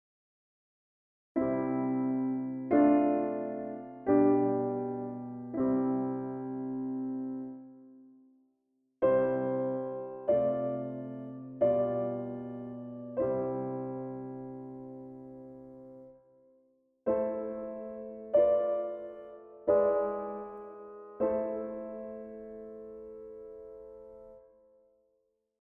Galant met 65 op (4) en 64 53 op(5) Galant met 63 op (4) en 64 53 op (5) inclusief Napels 6.
discant cadence met (1) (7) (1)